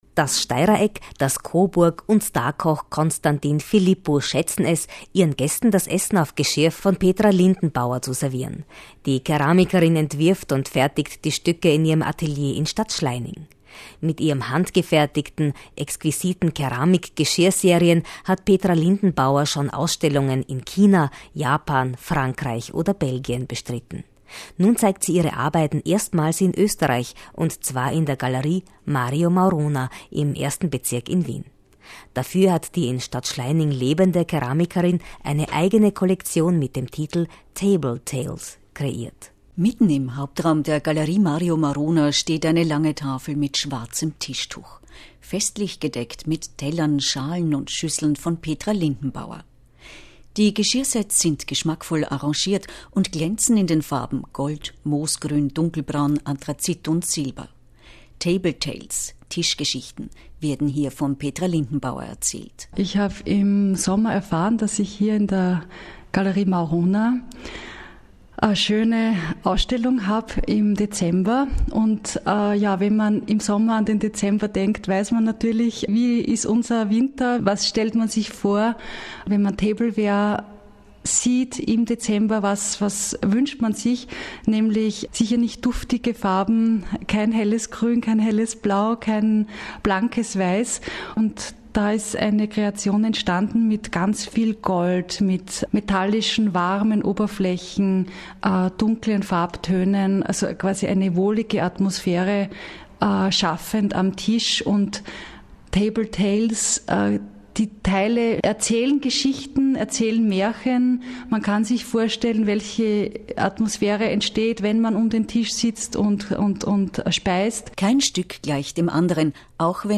Radiointerview für Ausstellung „Table Tales“